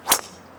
hit_hard.wav